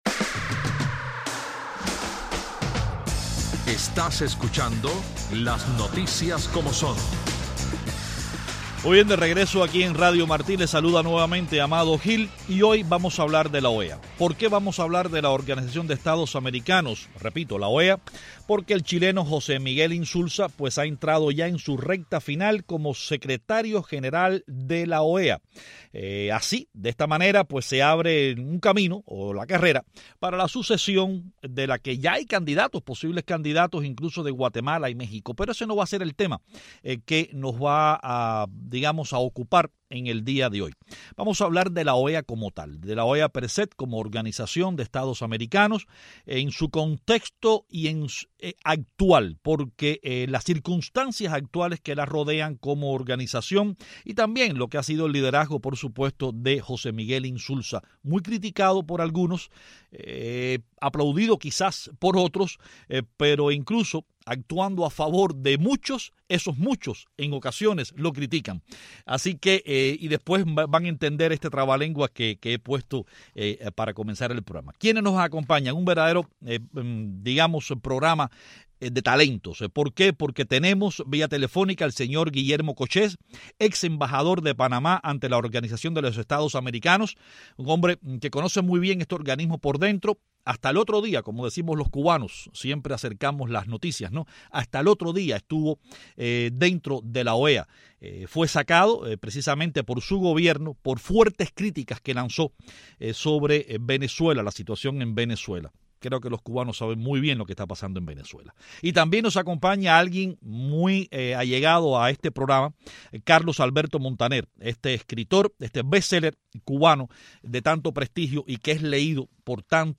¿Cómo ha cambiado la Organización de Estados Americanos (OEA), bajo el liderazgo del Secretario General José Miguel Insulza, cuyo mandato de10 años terminará en 2015? Nuestros invitados son el periodista cubano Carlos Alberto Montaner, y el ex representante de Panamá ante la OEA, Guillermo Cochez.